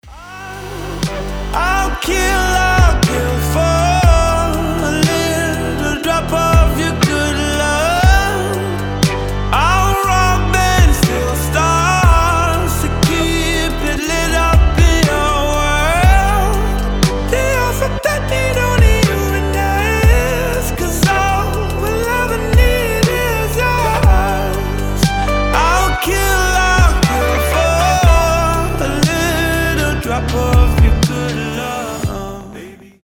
• Качество: 320, Stereo
alternative